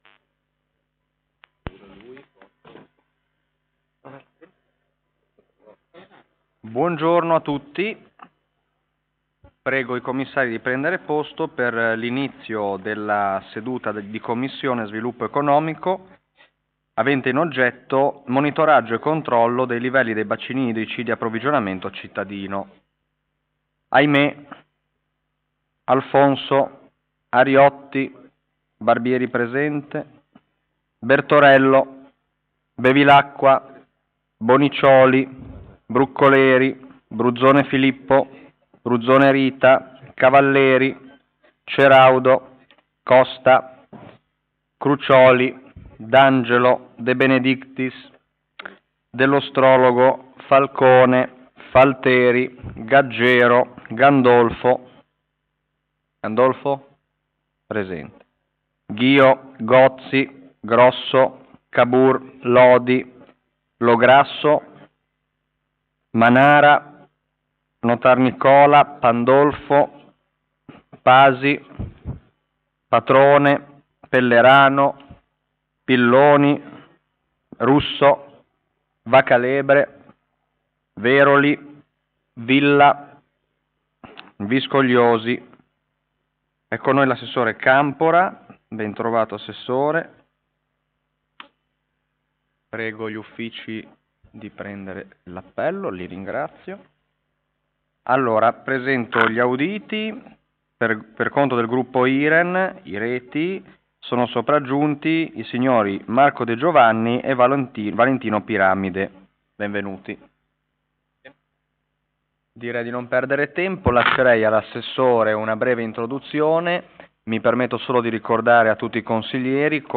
Commissione consiliare o Consiglio Comunale: 6 - Sviluppo Economico
Luogo: Presso la Sala Consiliare di Palazzo Tursi - Albini